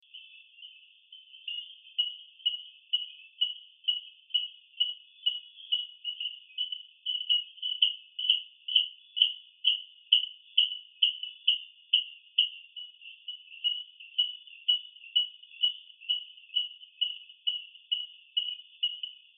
Coquí De Hedrick
Su llamada es una serie de pulsos que suenan como un martillo dando golpes en metal.